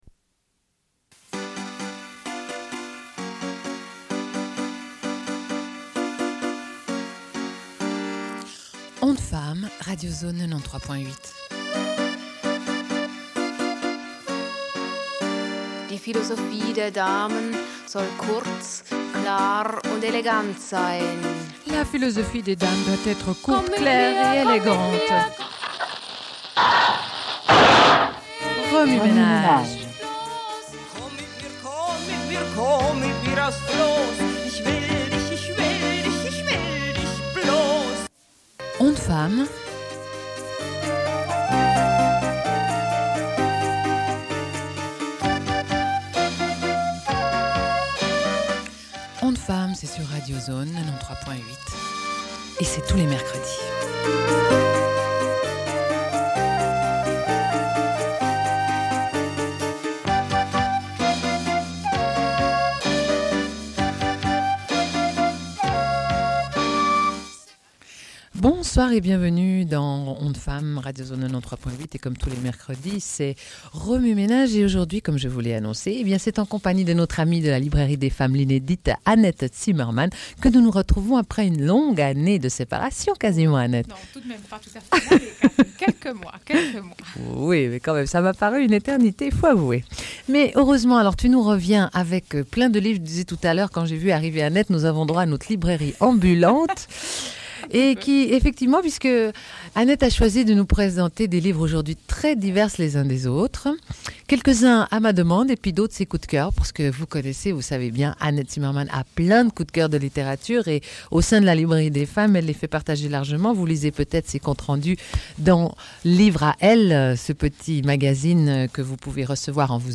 Une cassette audio, face A31:39